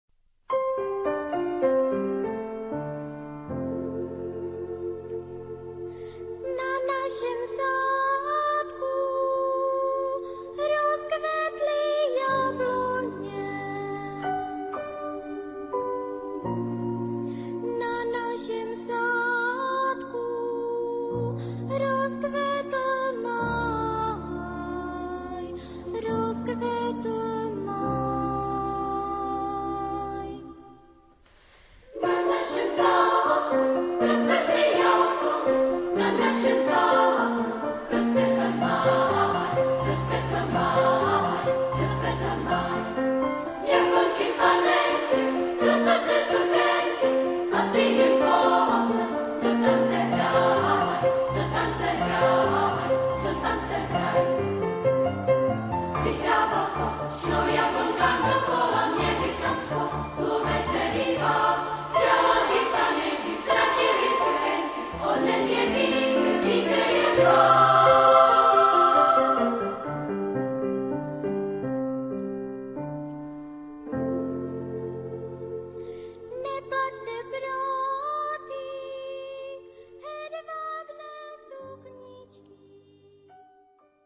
... 14 Lieder für Kinderchor ...
Type de choeur : SSA  (3 voix égale(s) d'enfants )
Instruments : Piano (1)
Tonalité : fa majeur